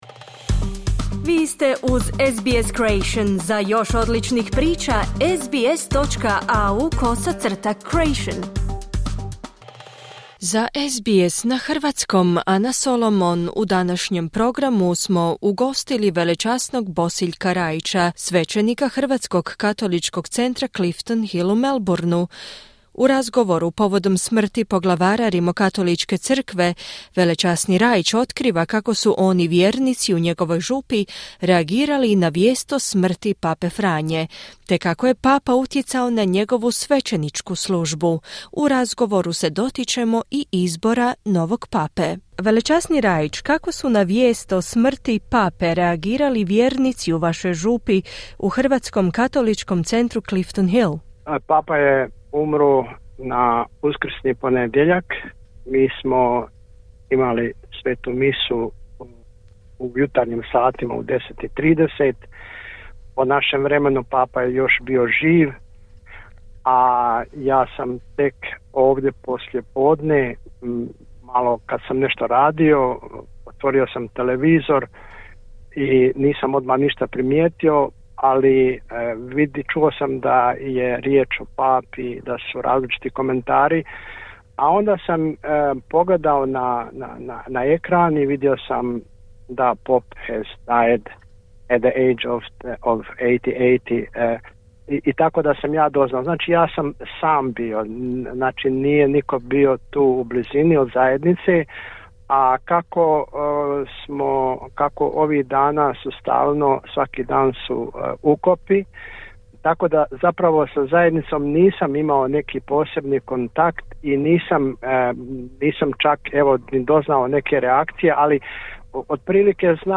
U razgovoru se dotičemo i izbora novog pape.